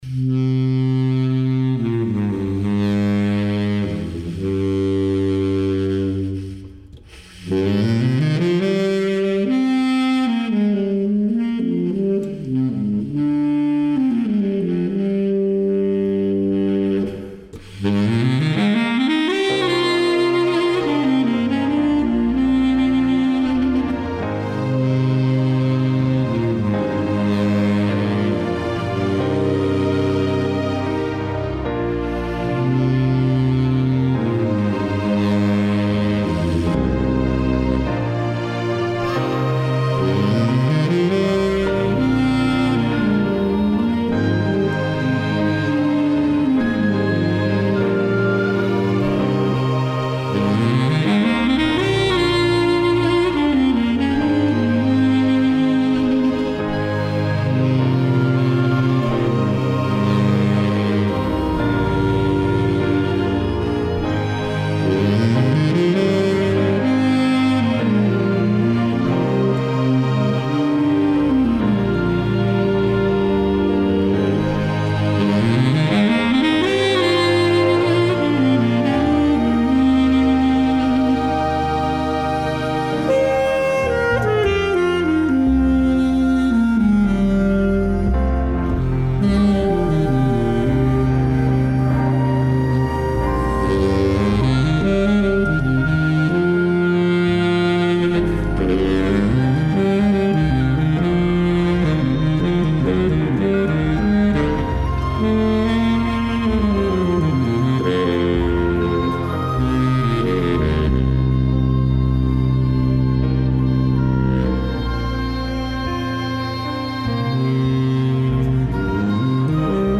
Suite for Baritone Sax